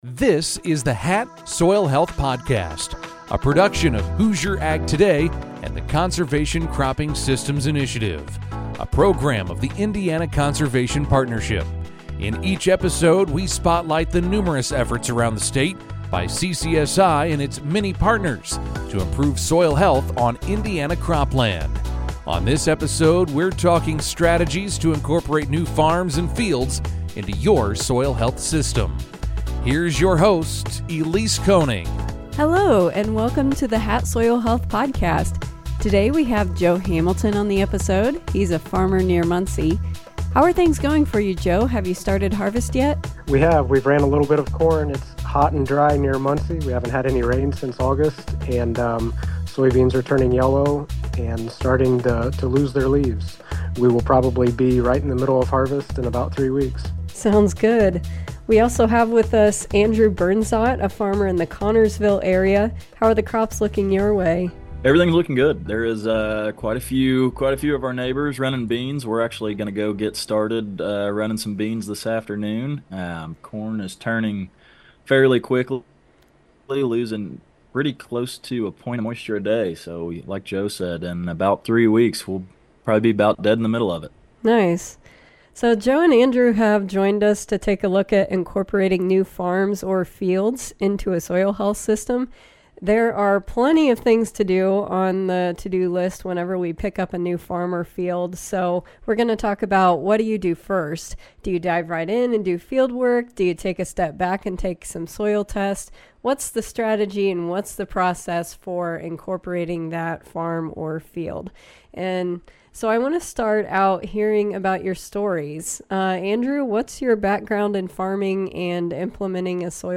It features farmers and other experts discussing a wide variety of practices: no-till, cover crops, nutrient/manure management, grazing.